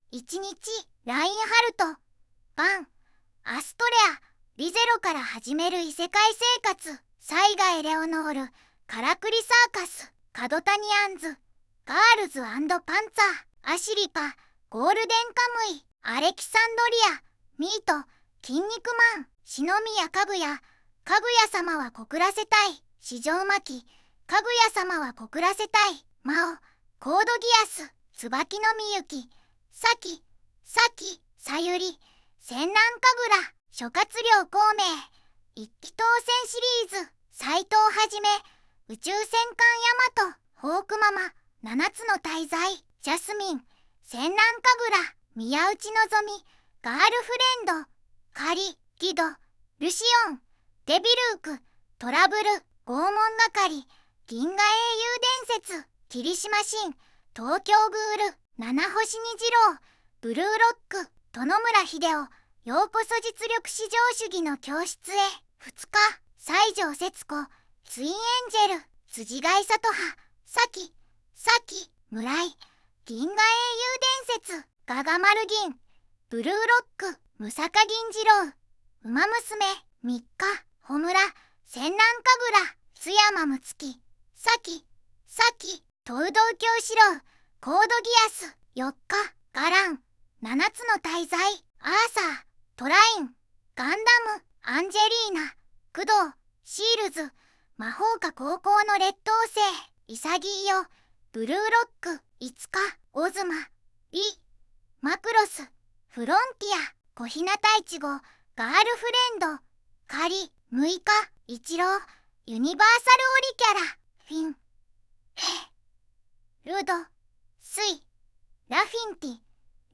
※音声読み上げは読みがおかしかったり、新規追加キャラクターは音声読み上げされない事があると思います。
VOICEVOX: ずんだもんを利用しています